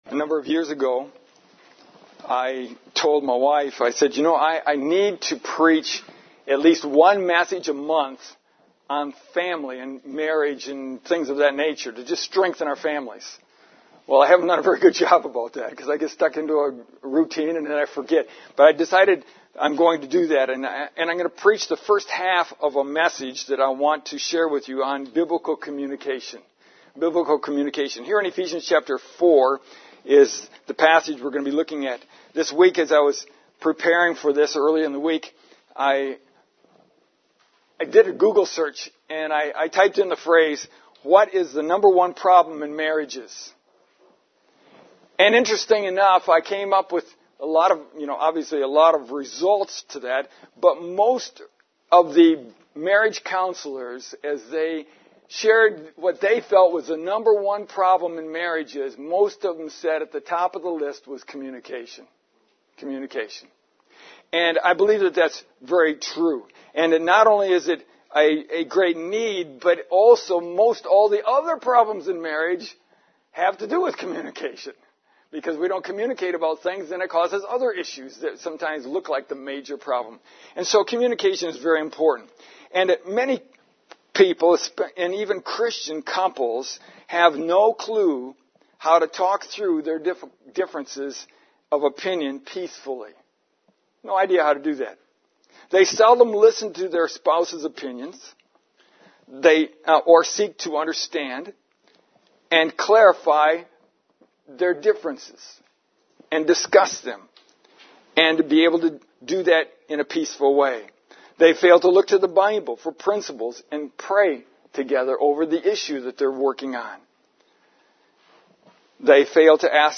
This is the first in a couple of messages I want to preach on Biblical Communication.